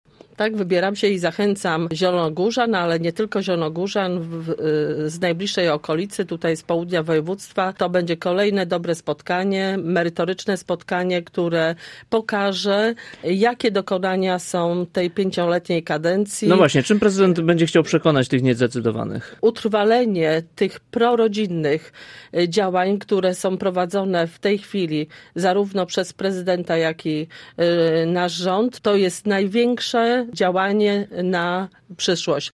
Miejska radna Bożena Ronowicz zachęca również zielonogórzan do udziału w tym spotkaniu: